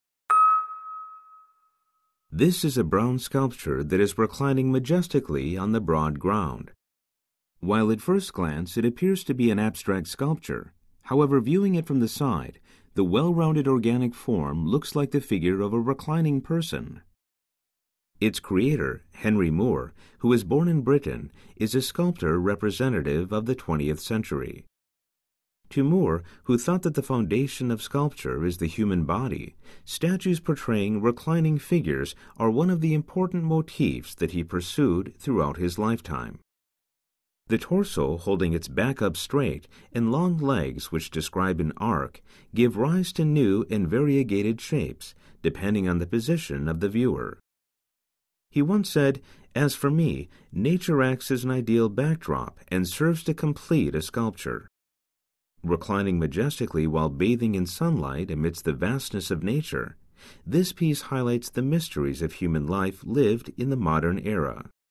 THE HAKONE OPEN-AIR MUSEUM - Audio Guide - Henry Moore Reclining Figure: Arch Leg 1969-70